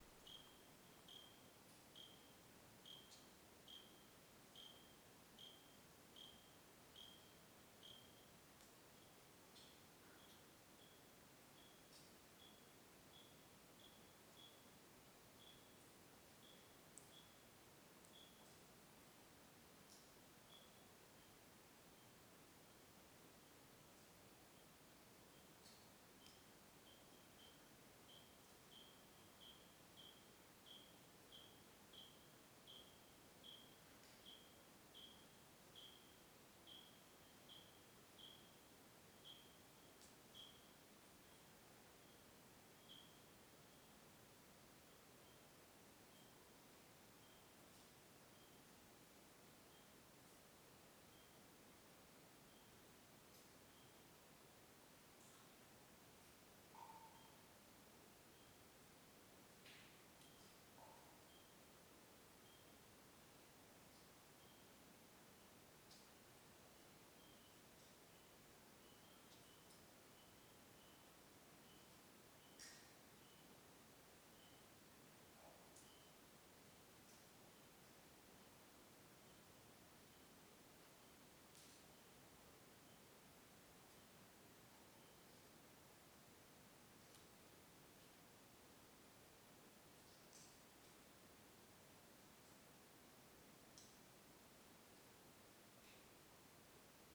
CSC-05-174-OL- Grilo discreto no interior de caverna a noite .wav